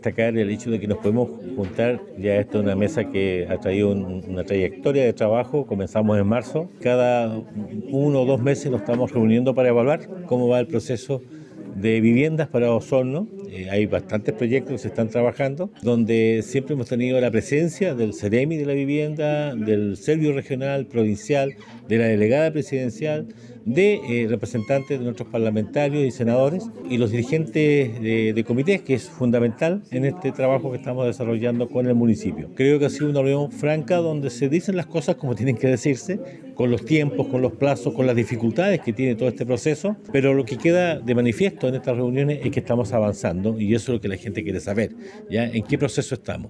El Alcalde de Osorno Emeterio Carrillo, realizó un positivo balance de la sesión de este mes de la mesa comunal de vivienda, ya que se ha realizado un trabajo continúo desde marzo con los actores de este tema y se ha entregado información continuamente a las 1300 familias que esperan una solución habitacional.